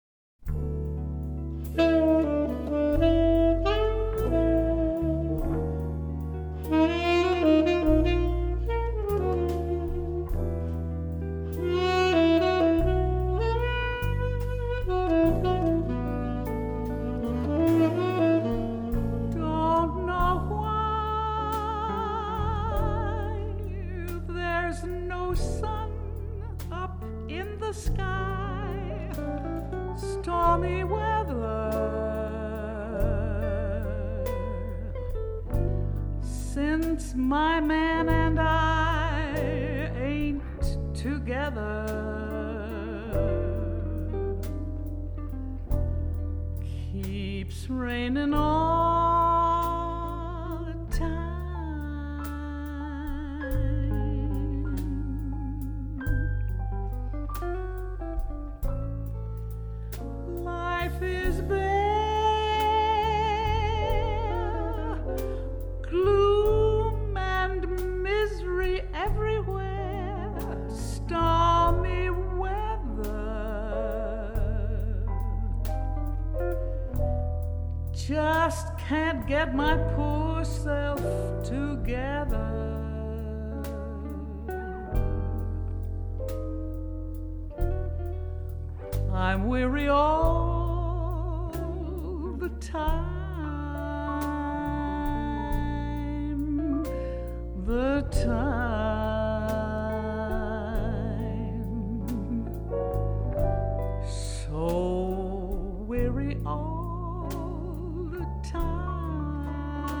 Stereo, Out of Absolute Phase 立體聲、絕對相位反相